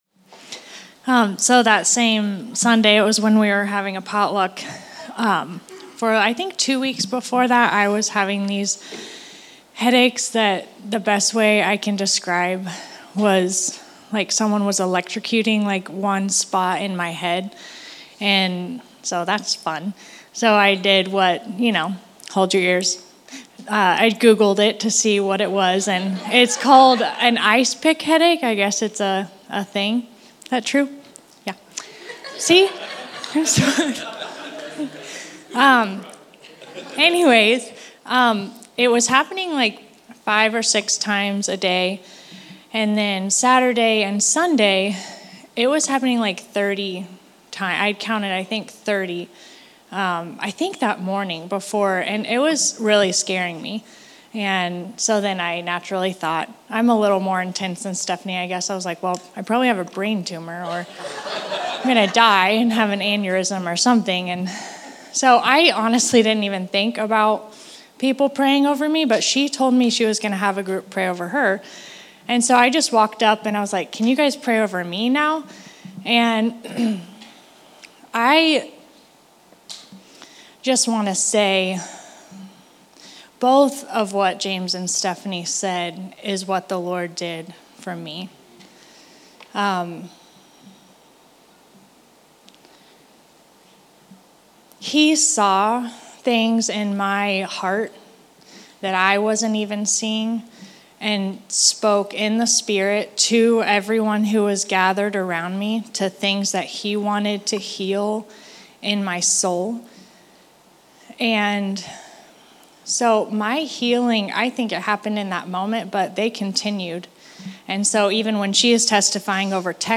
Healing Testimony & Encouragement